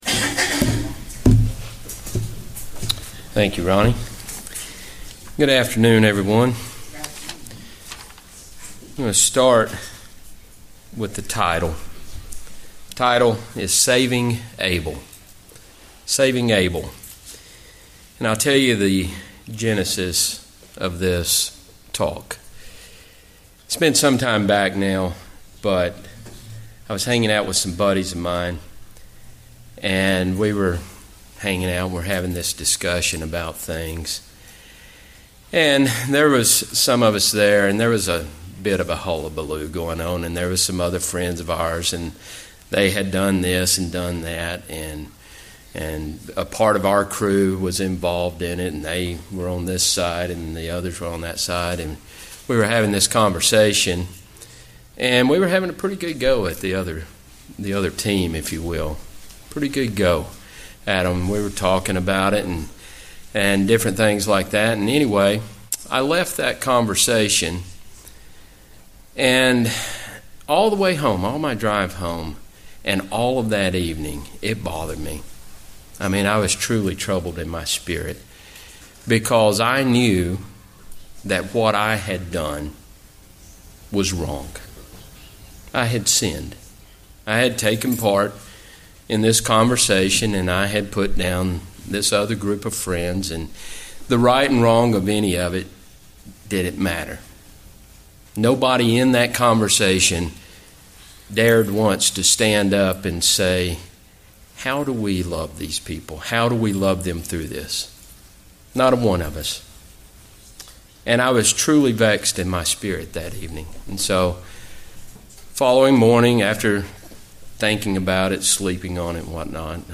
Sermons
Given in Huntsville, AL